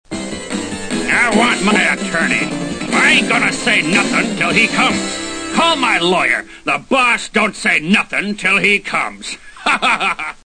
And here's some of the wonderful voice acting.
How could anyone with a voice like that possibly be evil?